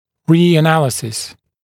[ˌriːə’næləsɪs][ˌри:э’нэлэсис]повторный анализ